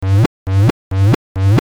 硬式后踢
描述：另一个硬式踢腿向后。
标签： 160 bpm Hardstyle Loops Drum Loops 309.00 KB wav Key : Unknown
声道立体声